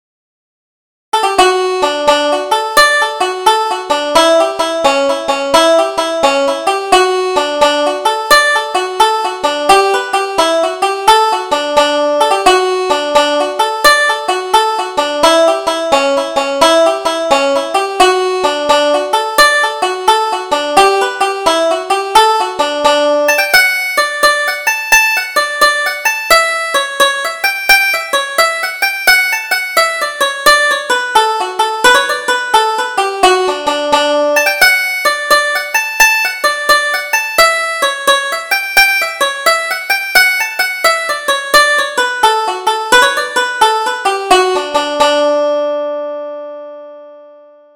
Double Jig: The Cat in the Corner